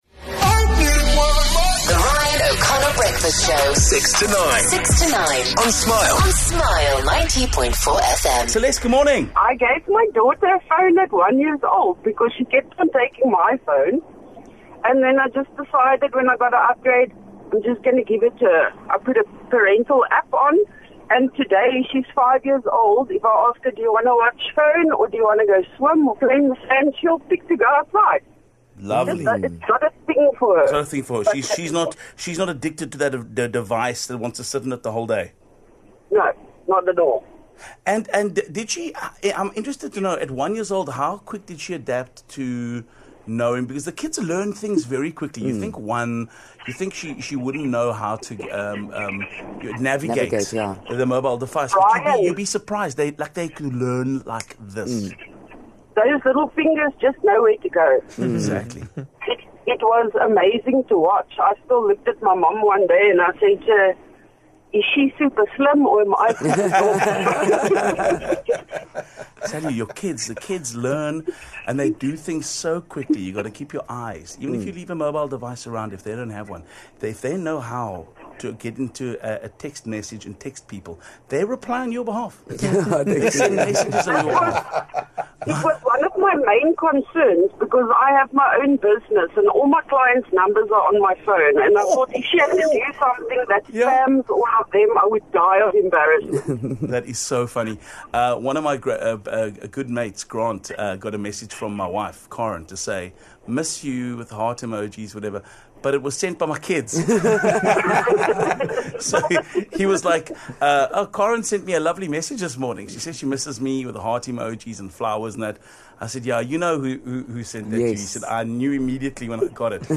You may remember a conversation around the topic of kids using phones and their safety. We got a call from a parent who gave her child a phone when she was just a year old.